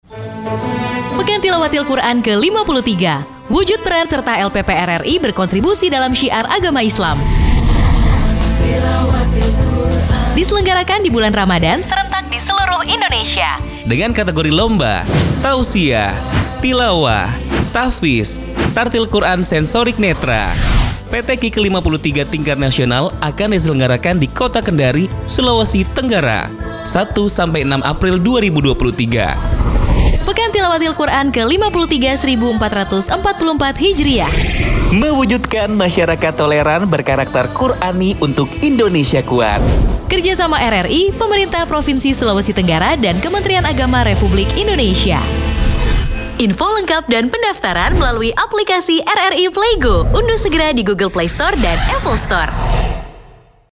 RADIO SPOT PROMO PTQ RRI KE-53 TAHUN 2023